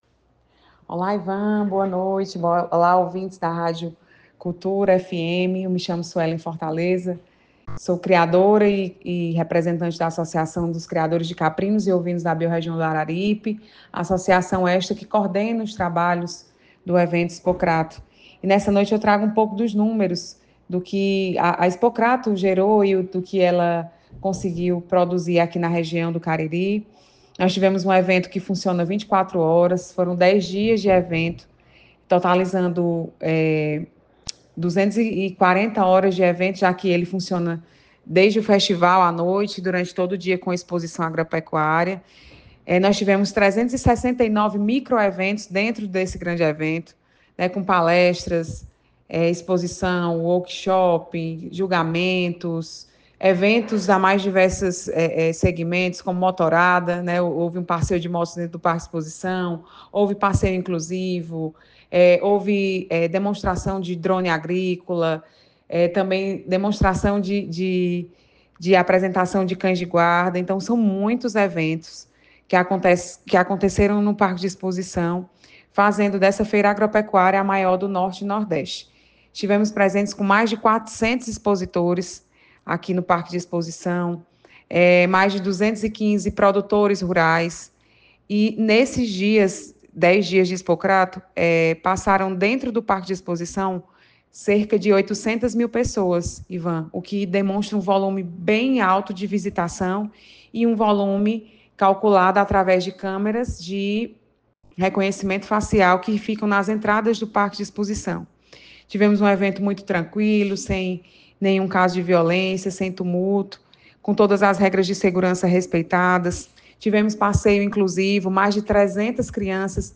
no Jornal Giro 360, da Rádio Cultura, na noite desta segunda-feira, 21.